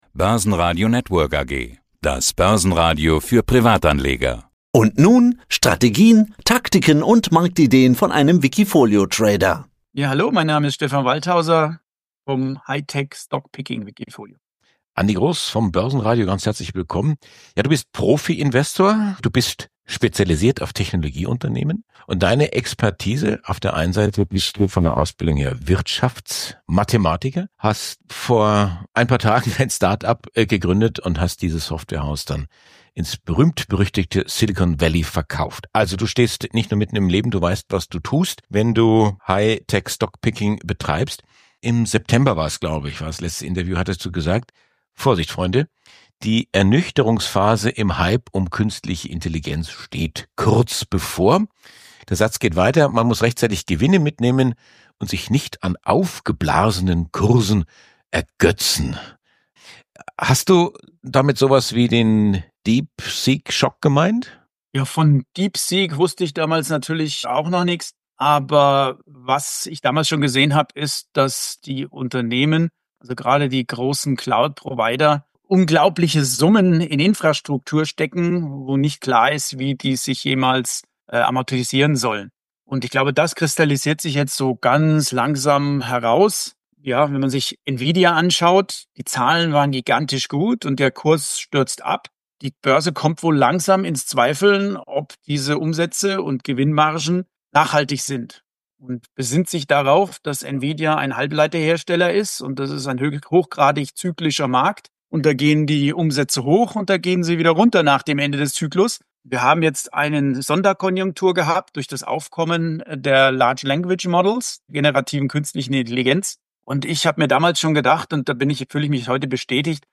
Börsenradio Interview Podcast